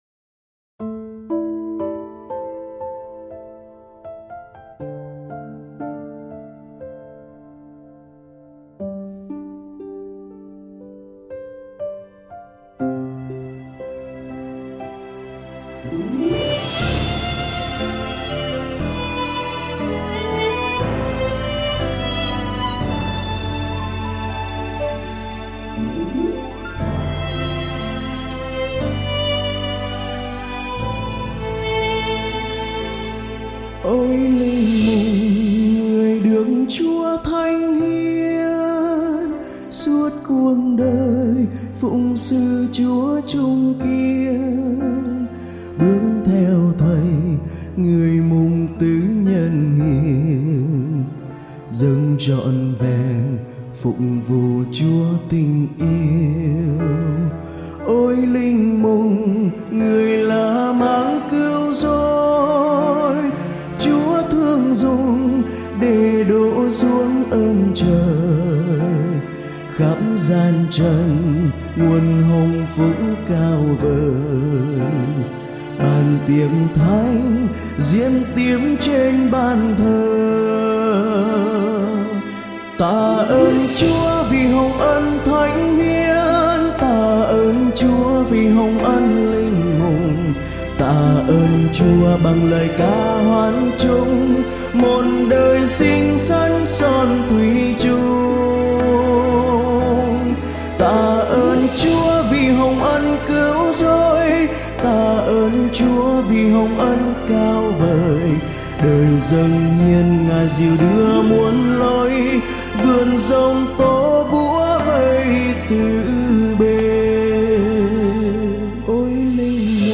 Nghe nhạc thánh ca. Bài hát được phát từ Website